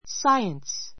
science 小 A1 sáiəns サ イエン ス 名詞 ❶ 理科 , 自然科学 ⦣ natural science ともいう.